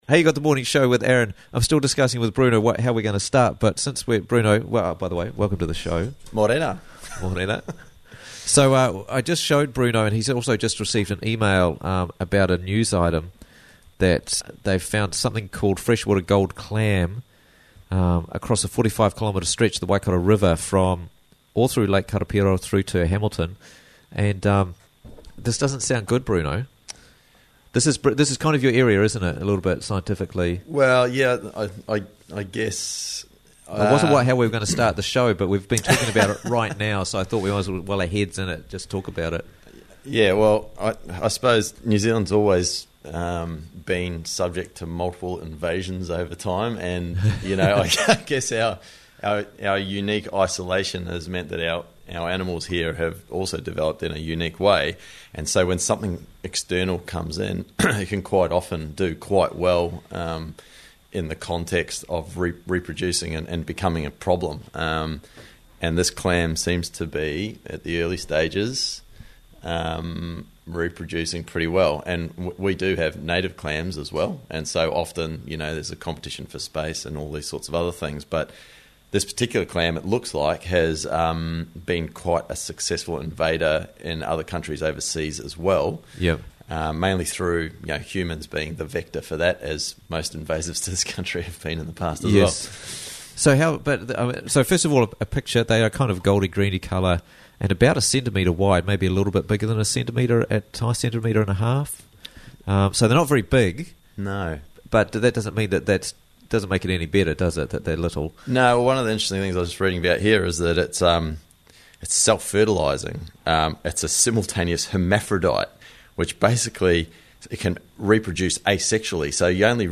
Interviews from the Raglan Morning Show